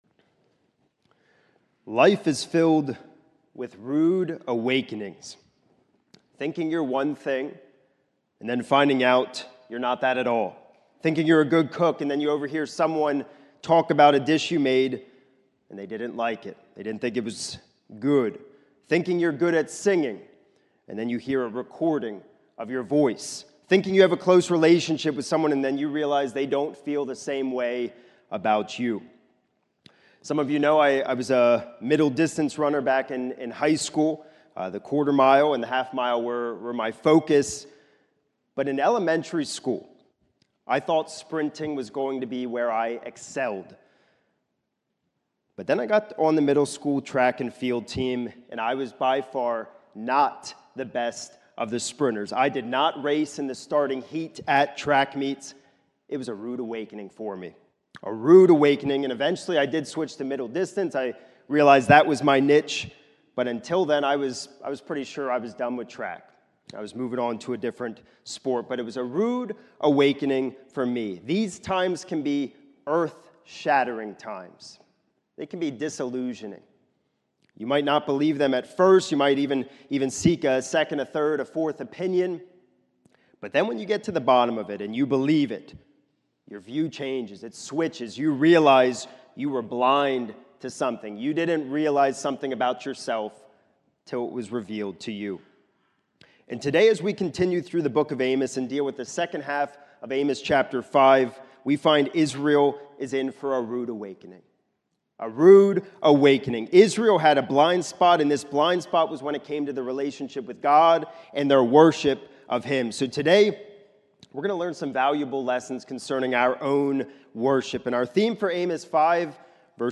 This is a sermon recorded at the Lebanon Bible Fellowship Church in Lebanon, PA during the morning service on 6/29/2025 titled, “Stop that Worship!”